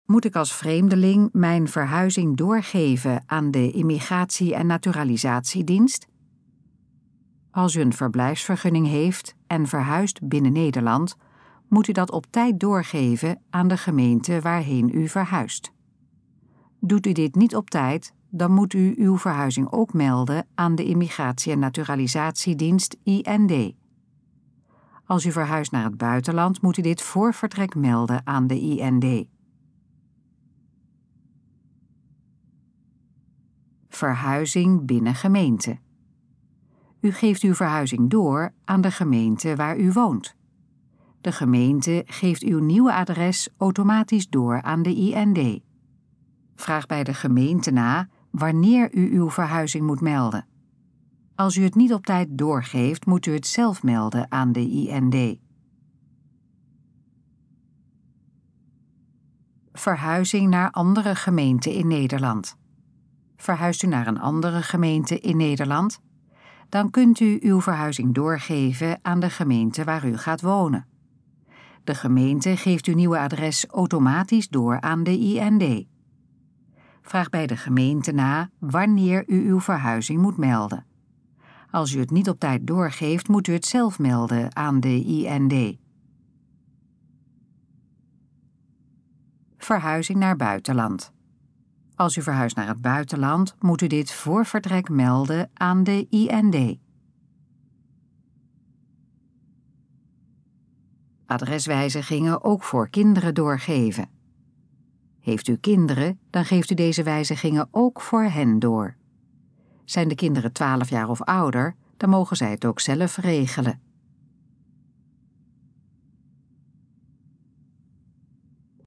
Dit geluidsfragment is de gesproken versie van de informatie op de pagina Moet ik als vreemdeling mijn verhuizing doorgeven aan de Immigratie- en Naturalisatiedienst?